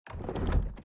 sfx_chest_jiggle_1.ogg